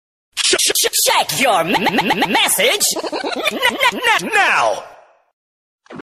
File Category : Free mobile ringtones > > Sms ringtones